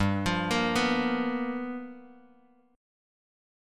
G7#9 chord